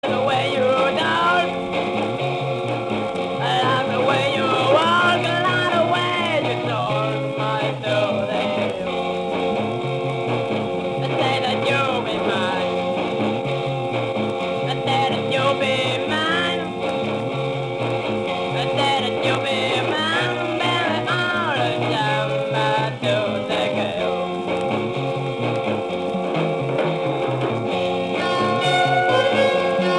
Heavy prog psyché